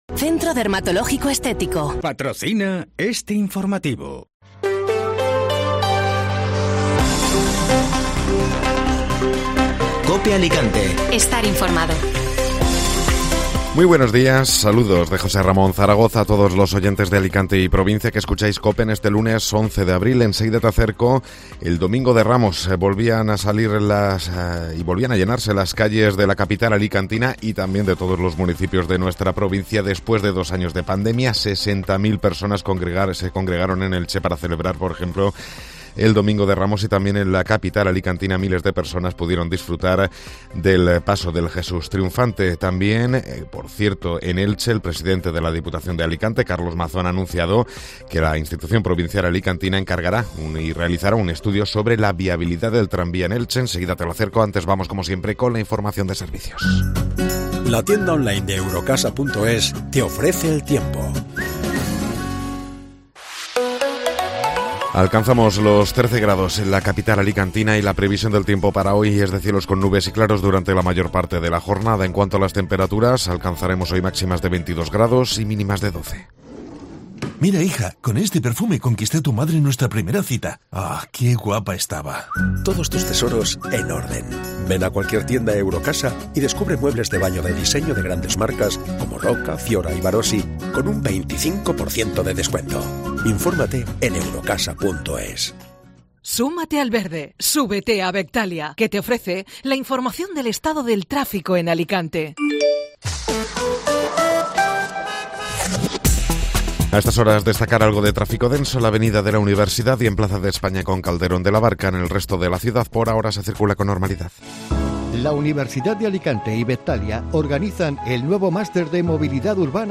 Informativo Matinal (Lunes 11 de Abril)